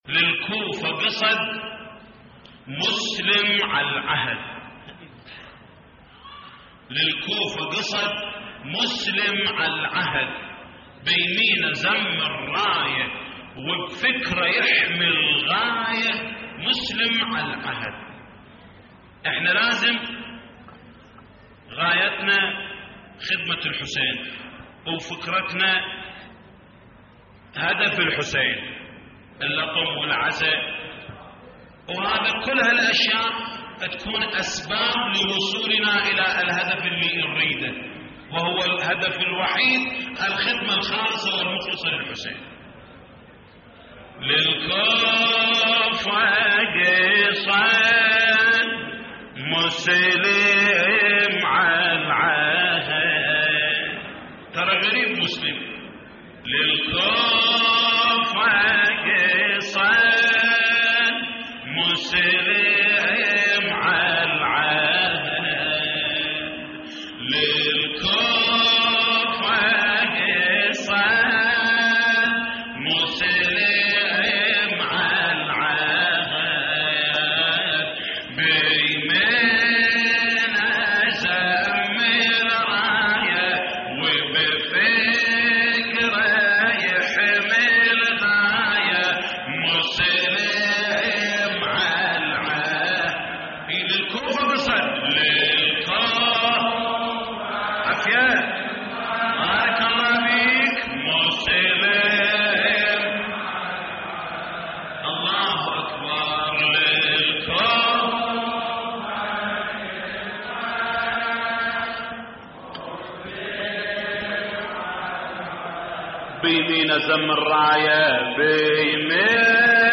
تحميل : للكوفة قصد مسلم على العهد بيمينه لزم الراية / الرادود جليل الكربلائي / اللطميات الحسينية / موقع يا حسين